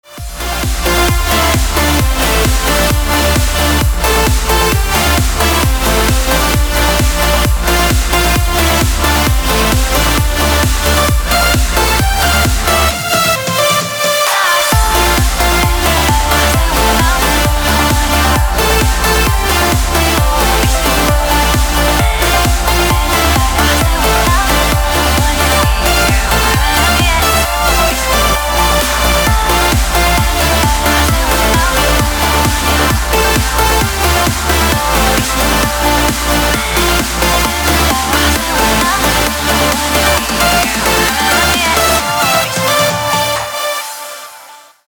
• Качество: 320, Stereo
громкие
dance
Electronic
электронная музыка
club
Trance